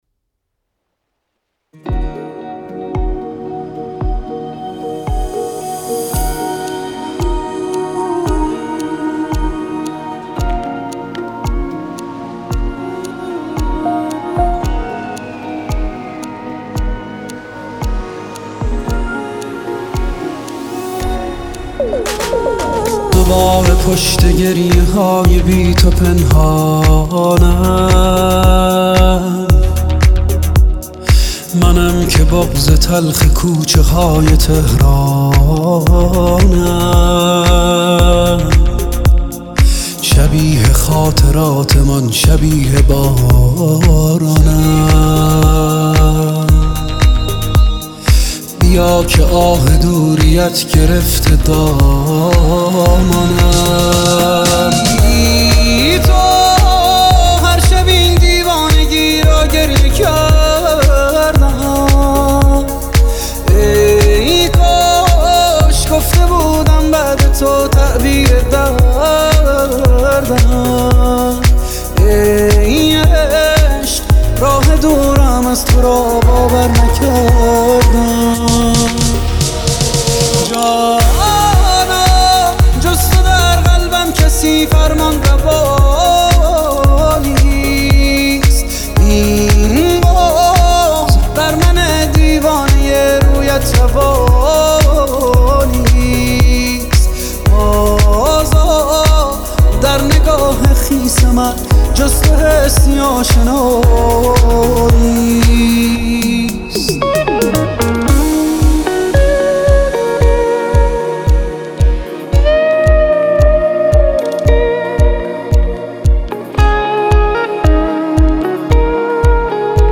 خواننده سبک پاپ است